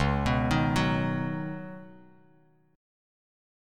Listen to C#9sus4 strummed